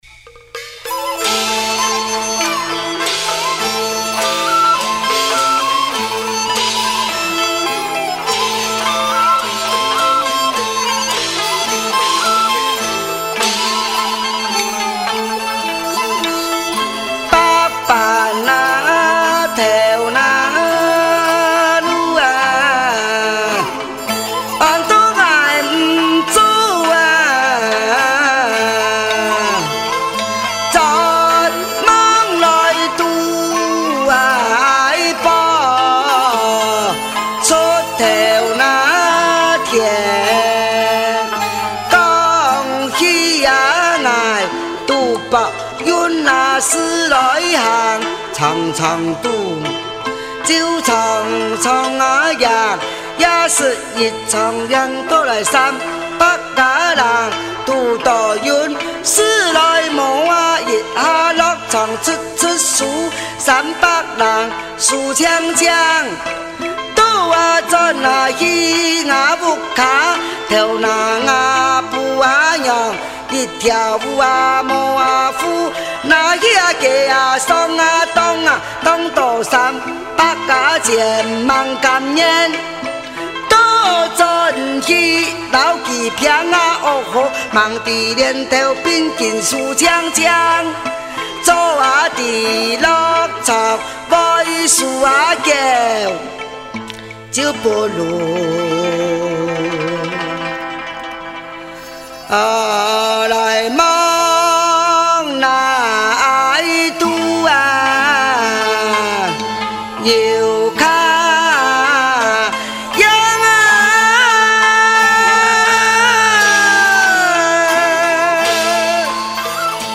【說南說北】（客家說唱